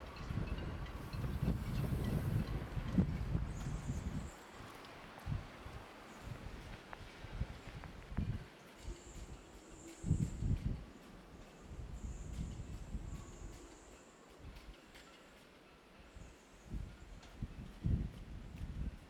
I was near Ramsgate’s marina at the end of the day. The wind was a bit strong, and whistled through the masts. Many boats still have Christmas lights on them, on top of the usual fixtures, all banging against the masts and sails, and each other.